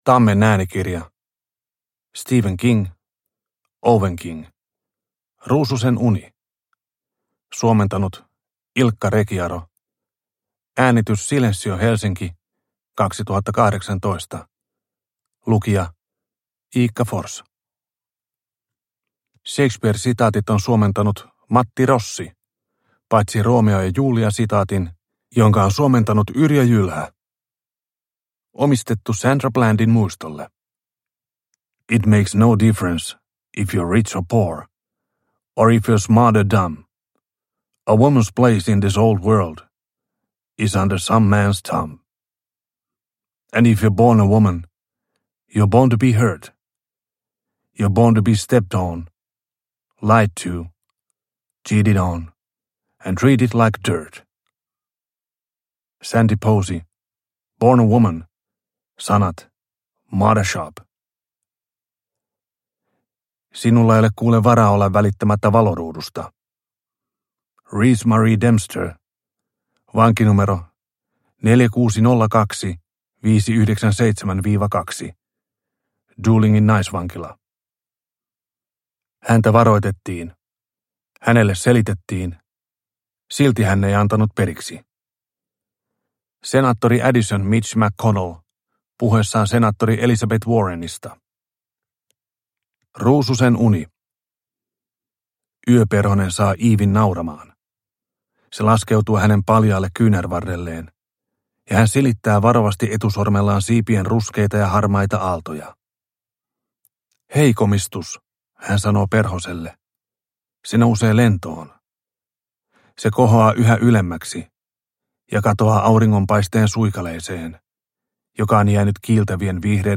Ruususen uni – Ljudbok – Laddas ner